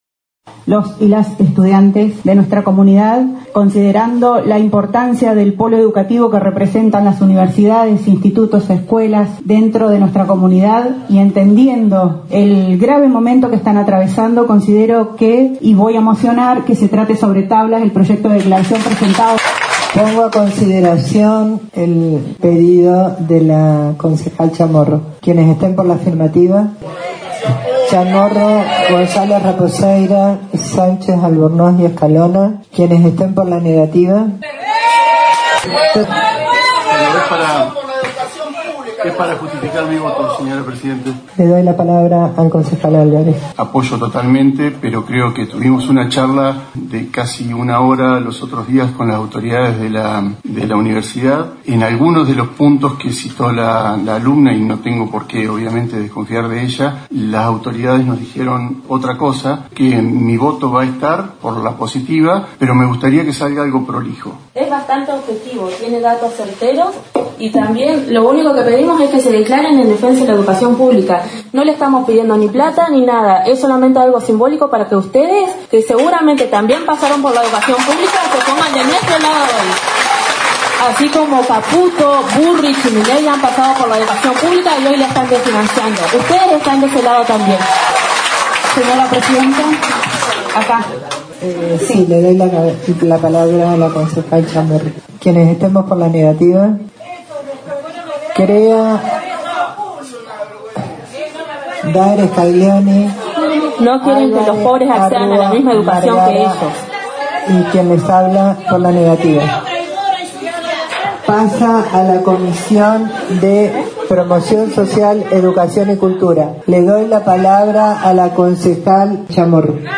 Luego de la presentación de las estudiantes, se produjo un debate entre los ediles y algunas de las personas presentes en el recinto de la sesión.